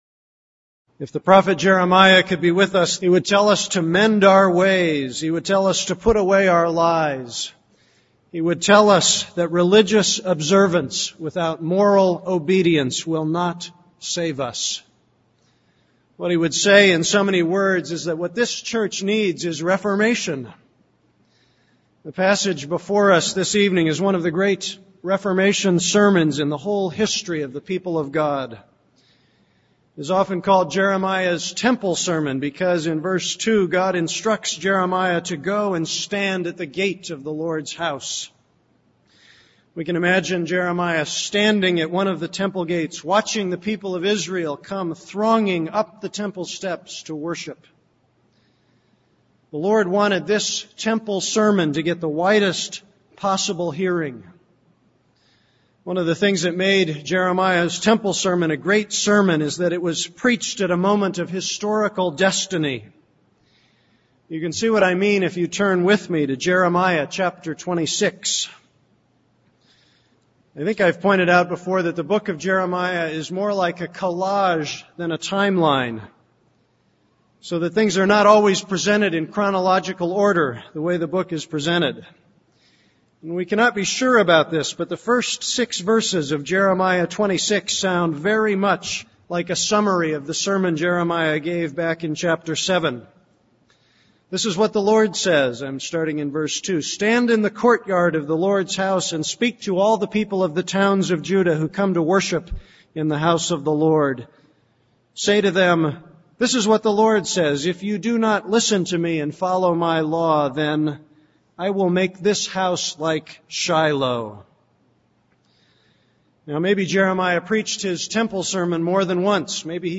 This is a sermon on Jeremiah 7:1-29.